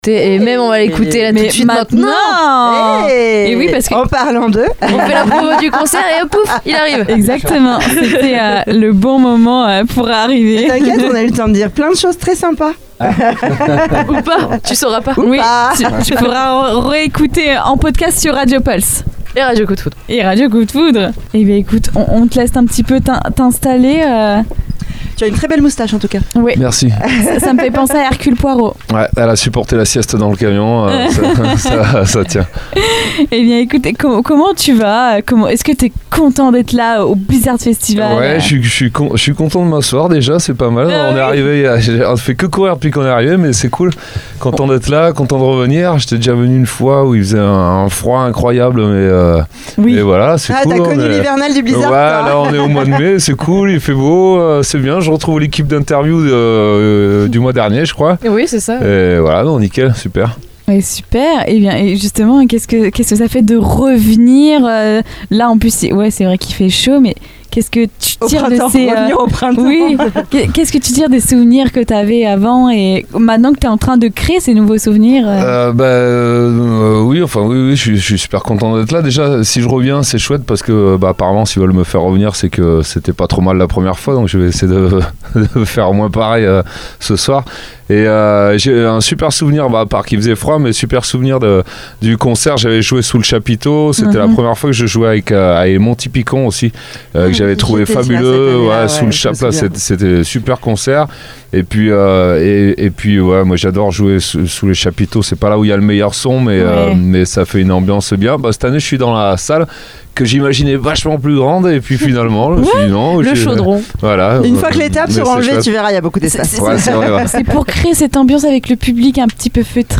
En pleine tournée, il a pris le temps de s’installer avec nous dans la Mystery Machine (studio radio aménagé dans une camionnette) pour une interview diffusée en direct sur Radio Pulse et Radio Coup de Foudre.